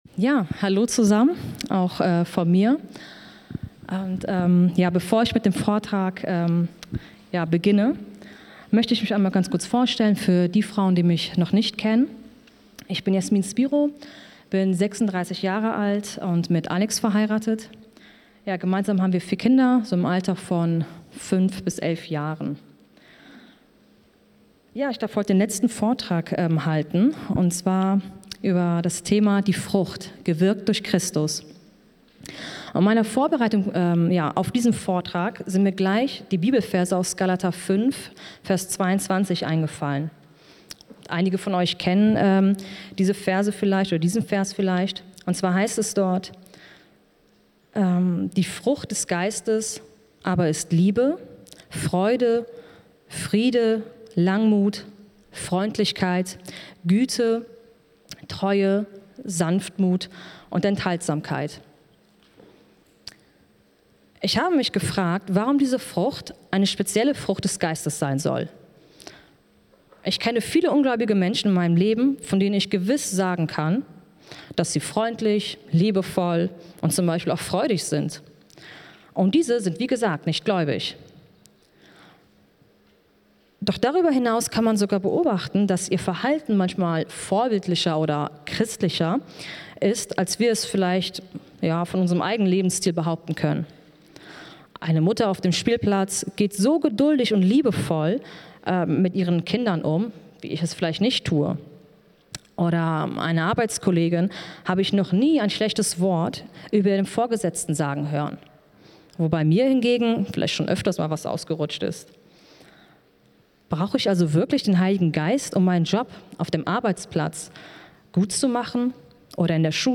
Frauenseminartage 2024 – 4. Vortrag: Die Frucht – gewirkt durch Christus